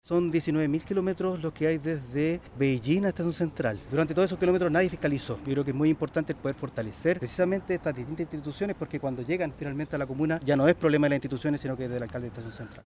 El alcalde de Estación Central, Felipe Muñoz, indicó que es imprescindible avanzar en proyectos de urbanismo táctico y responsabilizó la poca fiscalización de fronteras y Aduanas.